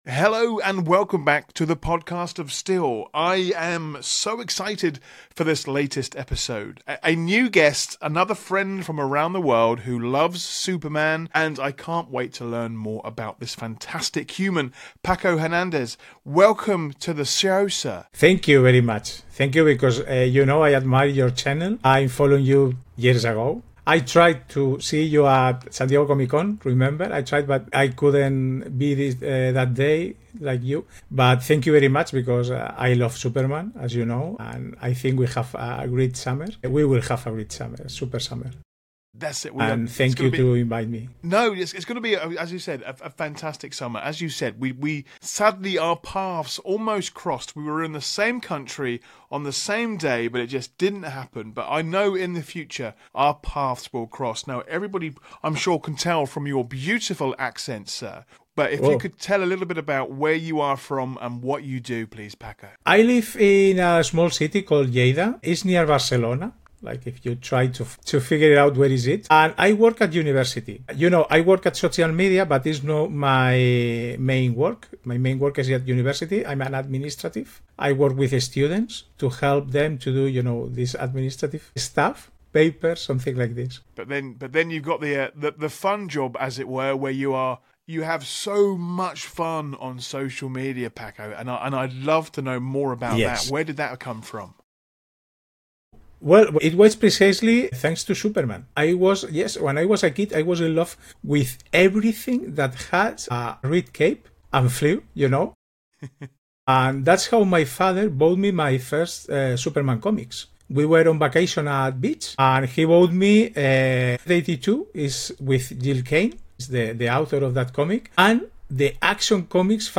a passionate chat about Superman, fandom, and the heroism we see in everyday life. We share what the Man of Steel means to us, swap Comic Con memories, and talk about the joy of finding fellow fans around the world. And of course, we dive into our excitement for James Gunn’s upcoming Superman movie and why the future looks bright for the Last Son of Krypton.